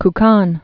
(k-kôn, -ôn)